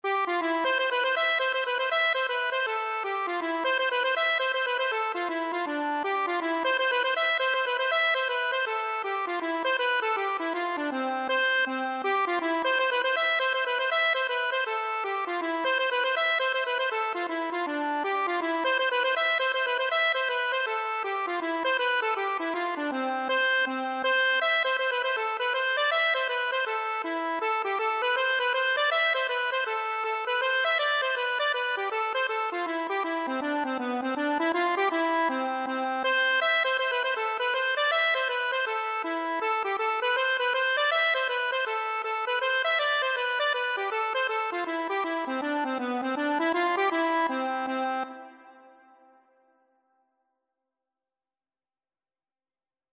C major (Sounding Pitch) (View more C major Music for Accordion )
2/2 (View more 2/2 Music)
Accordion  (View more Easy Accordion Music)
Traditional (View more Traditional Accordion Music)